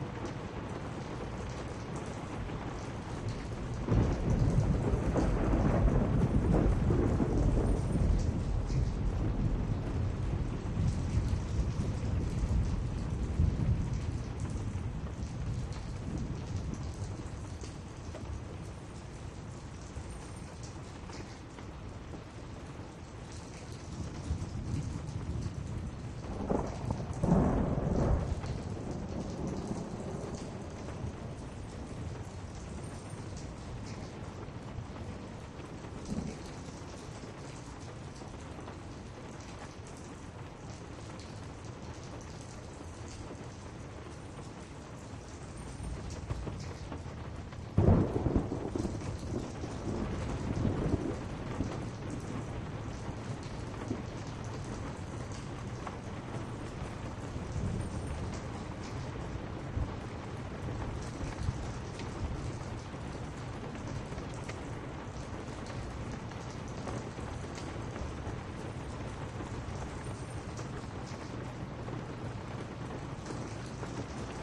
pomeschenie-snaruji-dojd-i-groza.ogg